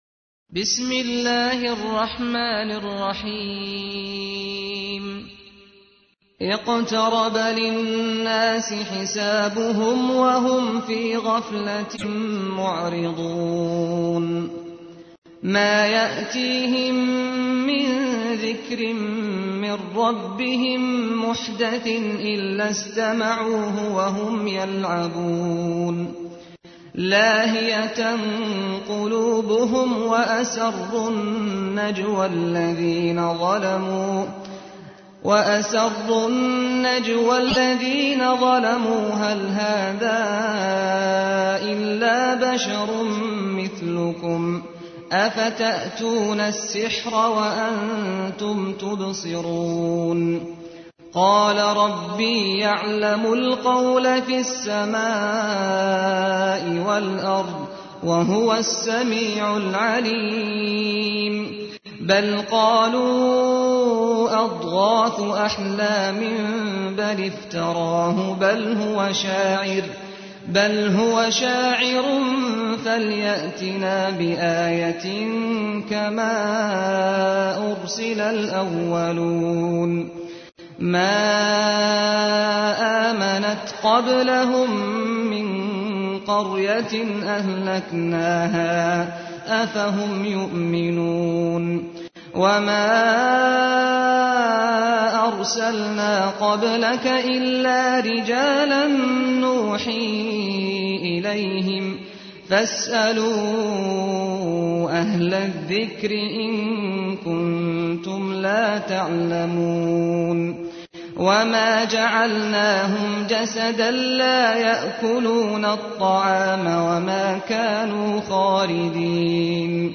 تحميل : 21. سورة الأنبياء / القارئ سعد الغامدي / القرآن الكريم / موقع يا حسين